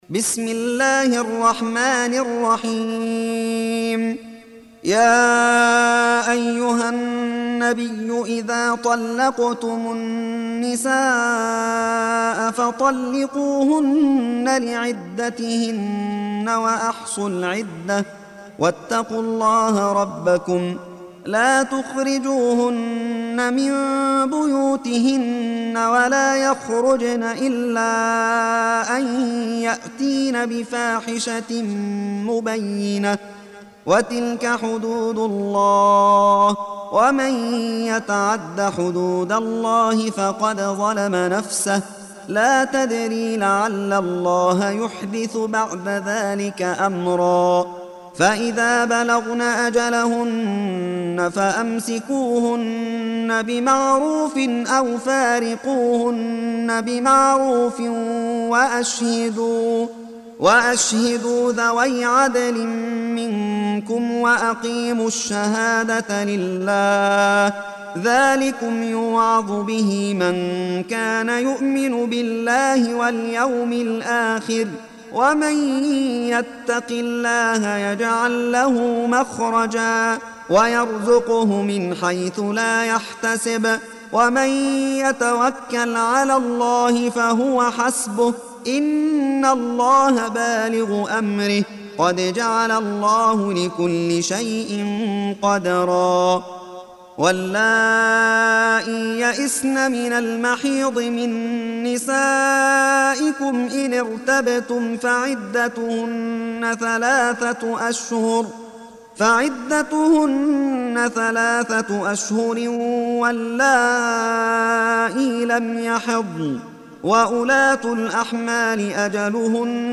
Surah Sequence تتابع السورة Download Surah حمّل السورة Reciting Murattalah Audio for 65. Surah At-Tal�q سورة الطلاق N.B *Surah Includes Al-Basmalah Reciters Sequents تتابع التلاوات Reciters Repeats تكرار التلاوات